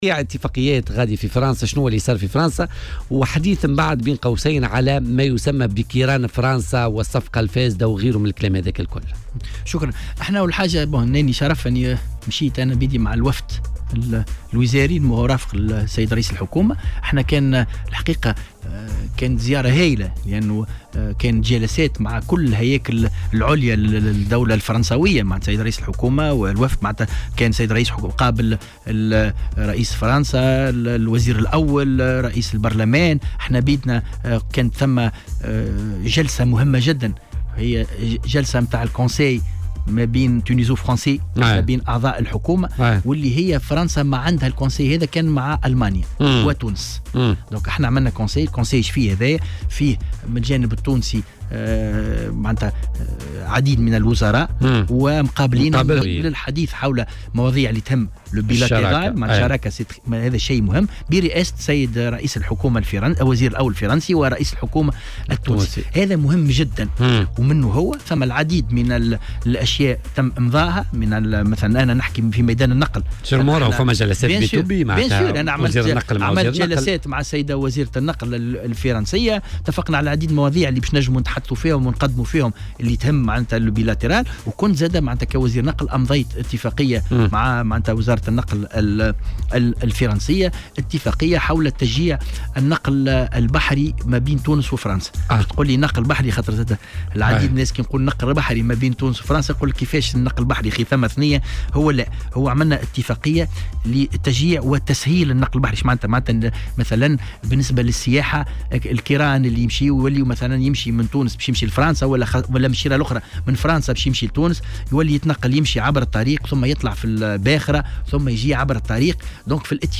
وأضاف ضيف "بوليتيكا" على "الجوهرة أف أم" أن القضاء بصدد القيام بتحقيقات بشأن هذه الصفقة وتم استدعاء عدد من الأشخاص والهياكل المعنية.